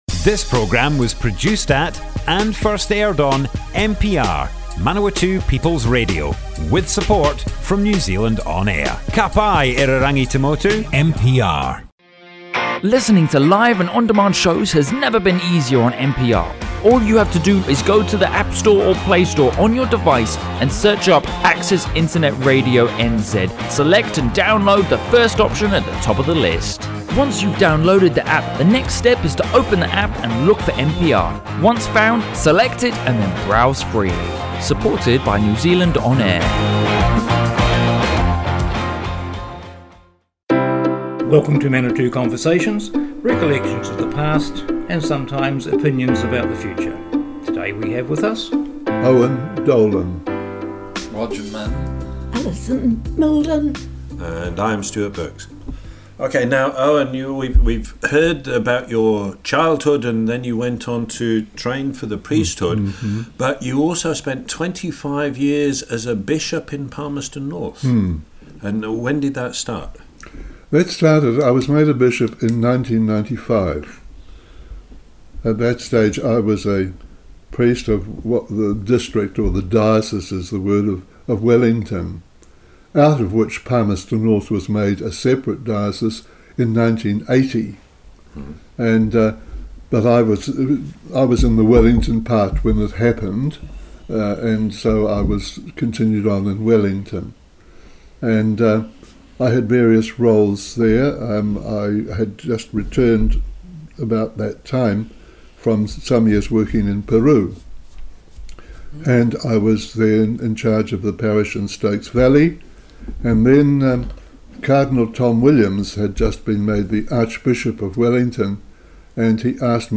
00:00 of 00:00 Add to a set Other Sets Description Comments Owen Dolan, missionary, bishop part 2 - Manawatu Conversations More Info → Description Broadcast on Manawatu People's Radio 9 October 2018.
oral history